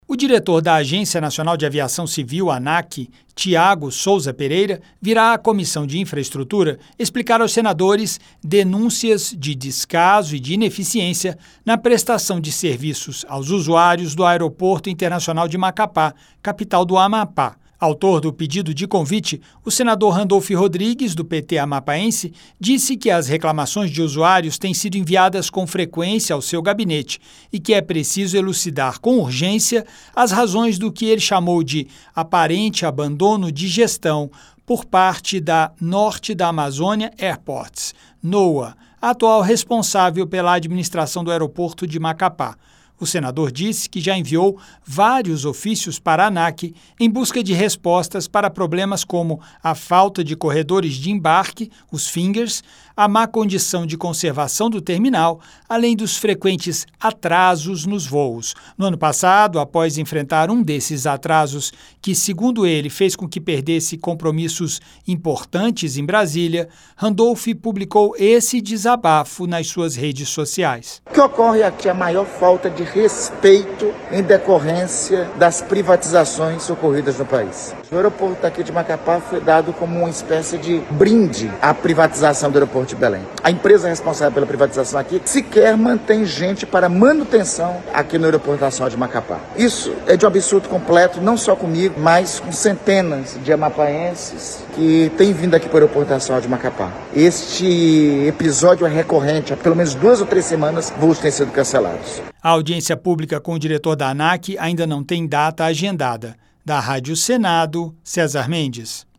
A Comissão de Infraestrutura aprovou um convite para que o diretor da ANAC, Tiago Sousa Pereira, venha trazer esclarecimentos aos senadores sobre as denúncias de descaso e de ineficiência na prestação de serviços aos usuários do Aeroporto Internacional de Macapá-AP. Autor do pedido de convite (REQ 32/2025-CI), o senador Randolfe Rodrigues (PT-AP) disse que os atrasos nos vôos são frequentes e reclamou também da falta de corredores de embarque e das más condições de conservação do terminal.